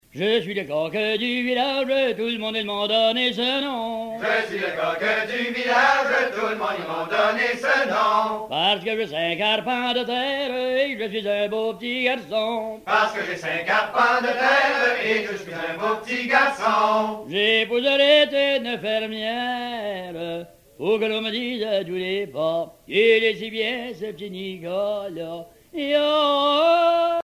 Genre énumérative
Pièce musicale éditée